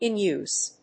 アクセントin úse